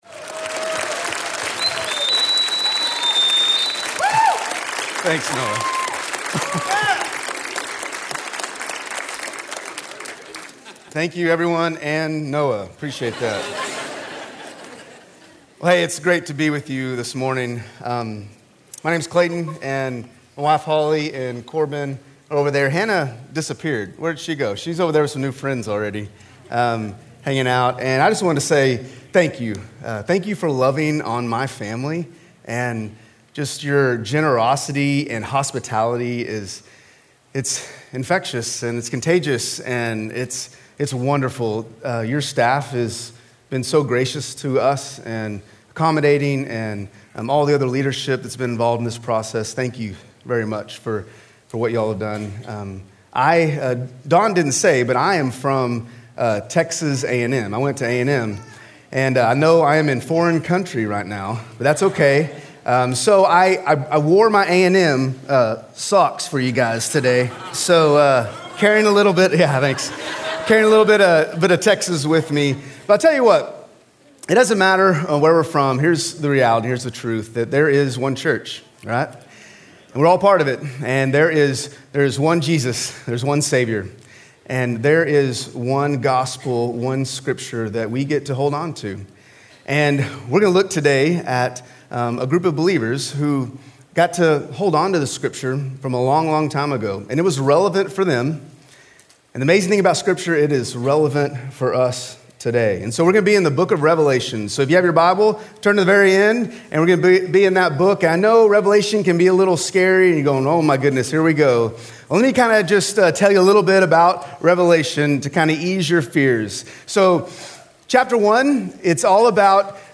A message from the series "Asking for a Friend."